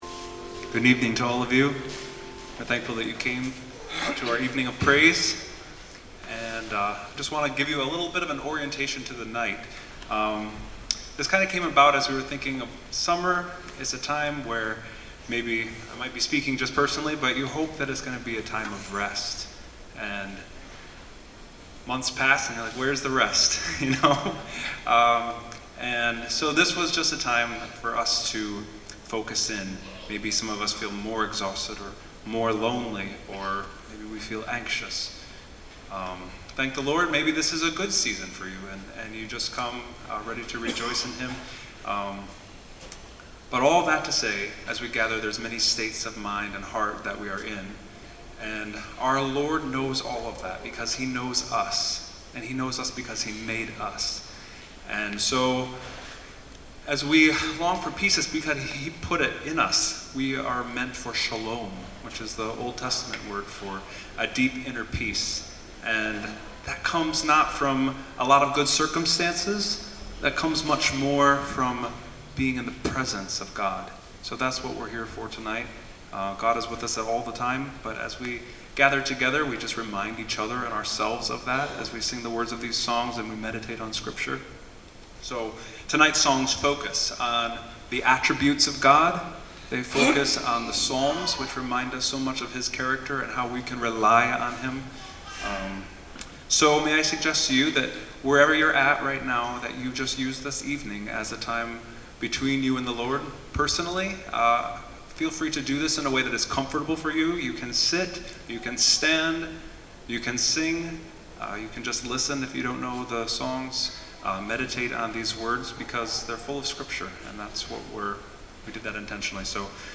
Below you will find information on music and events held outside our normal service times but that still work to serve our greater community. 2019 Evening of Worship